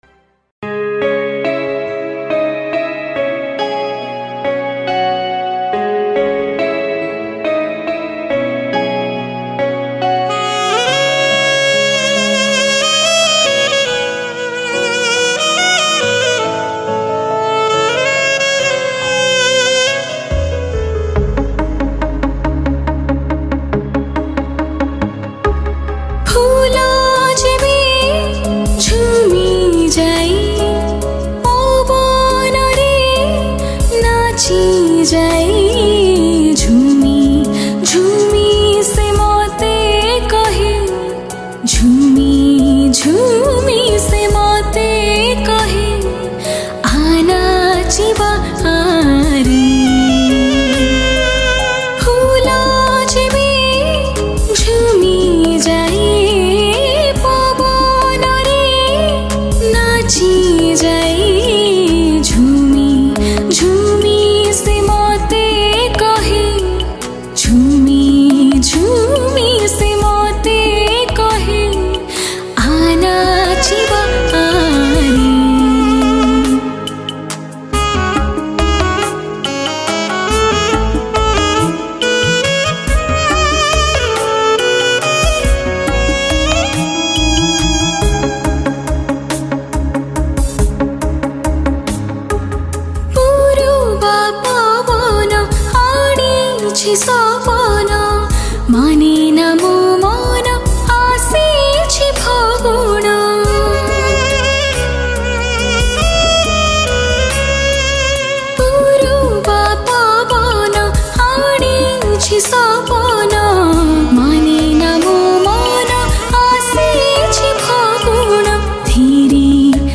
Odia Cover Song